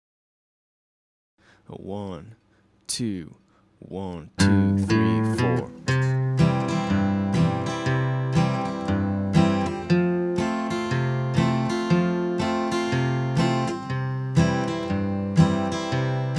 Country - Practice improvising using the C major scale over this typical three-chord (C, F, and G) country progression.
Country Jam Track 120bpm.mp3